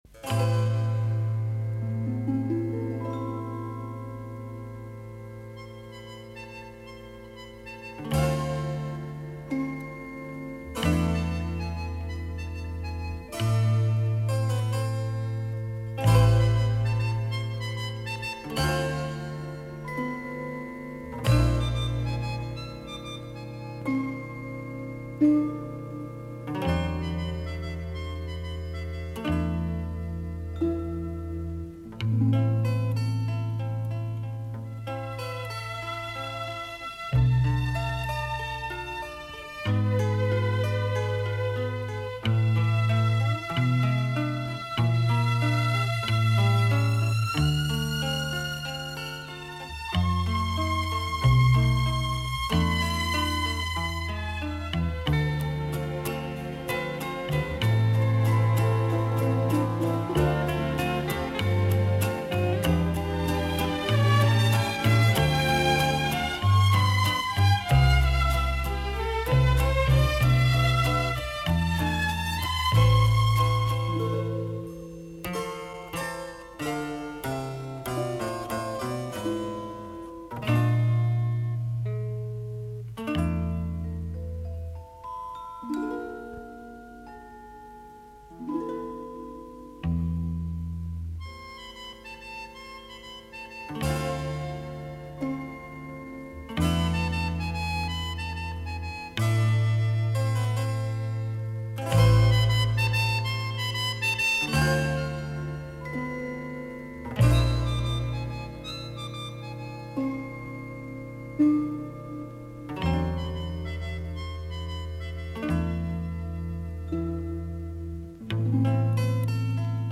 музыка из к/ф